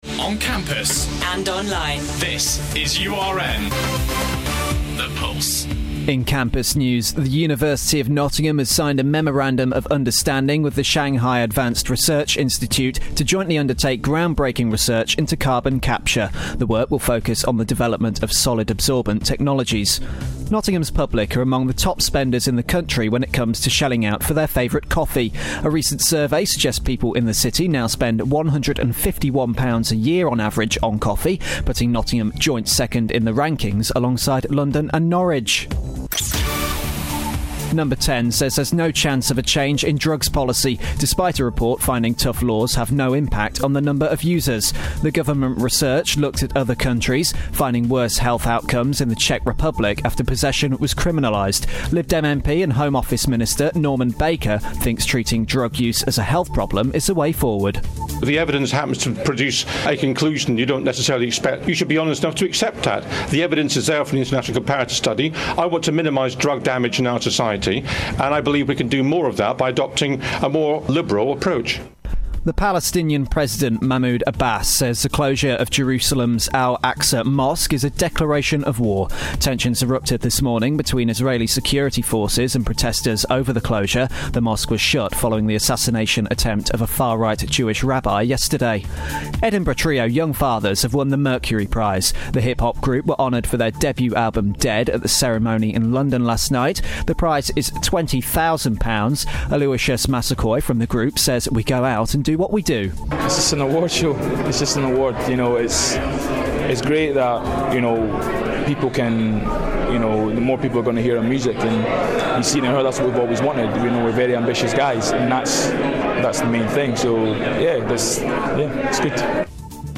Your Latest Headlines - Thursday 30th October